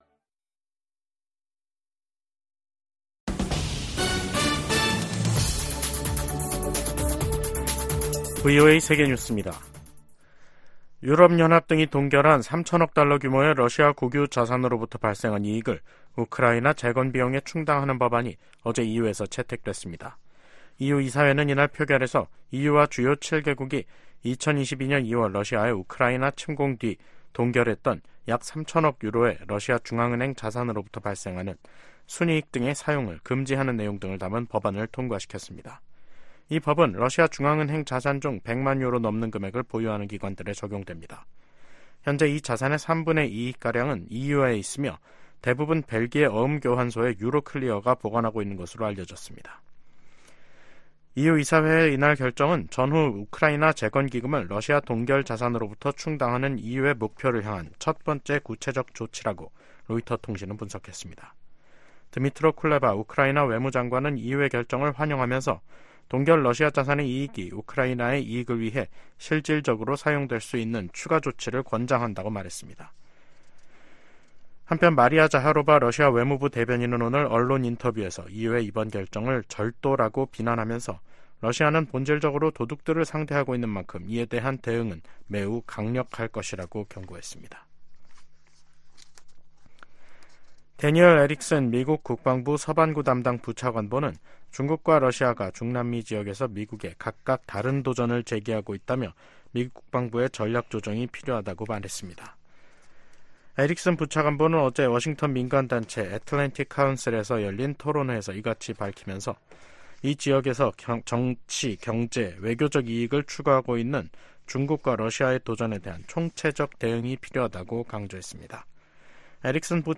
VOA 한국어 간판 뉴스 프로그램 '뉴스 투데이', 2024년 2월 13일 2부 방송입니다. 존 커비 백악관 국가안전보장회의(NSC) 전략소통조정관은 인도태평양 전략 발표 2주년을 맞아 대북 감시를 위한 한반도 주변 역량 강화가 큰 성과라고 평가했습니다. 러시아가 북한의 7차 핵실험 가능성 등을 언급하며 북한 입장을 두둔하고 있습니다. 로버트 켑키 미 국무부 부차관보는 미국·한국·일본이 북한-러시아 군사협력 대응 공조에 전념하고 있다고 밝혔습니다.